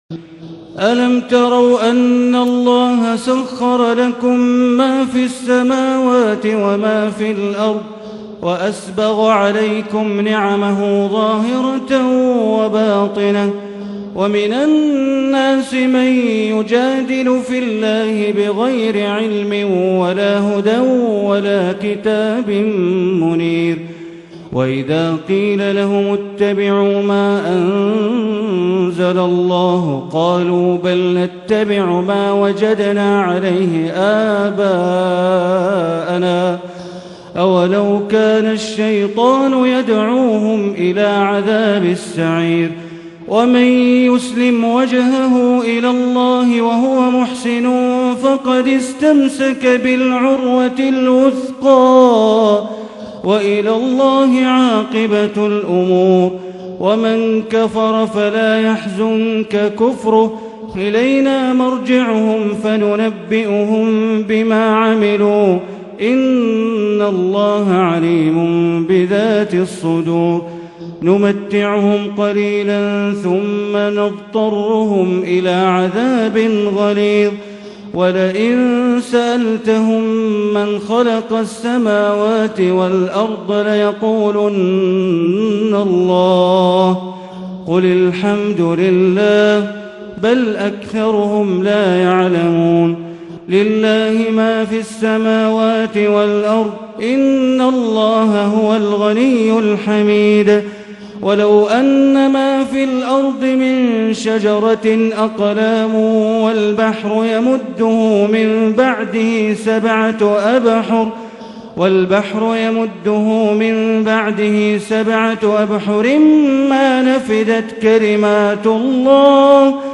ليلة ٢٠ رمضان 1440 من سورة لقمان و السجدة والاحزاب اية ٢٠ إلى ٣٤ اية > تراويح ١٤٤٠ هـ > التراويح - تلاوات بندر بليلة